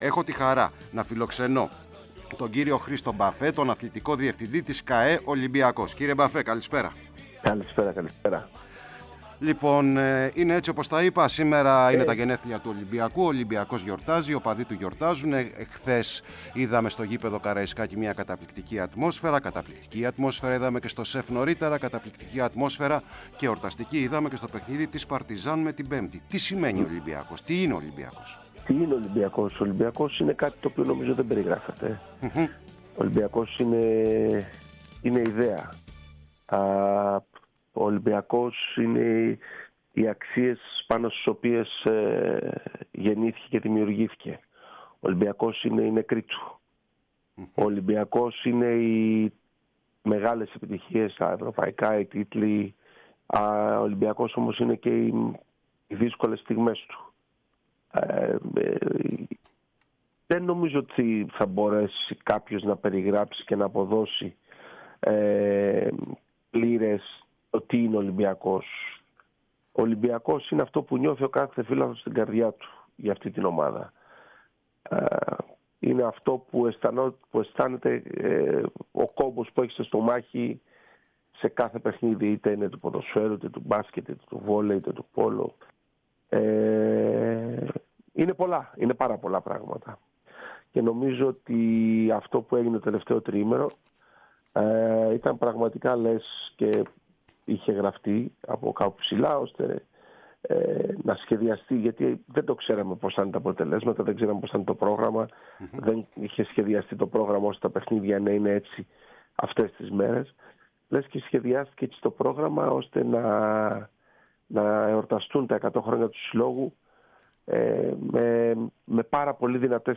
στην εκπομπή “Τελευταία Σελίδα” της ΕΡΑ ΣΠΟΡ